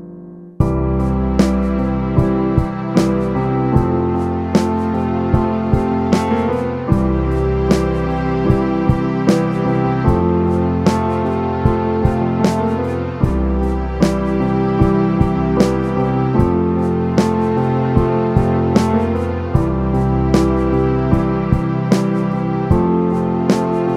Pop (1970s)